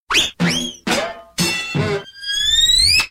golpes cartoon quilombo 01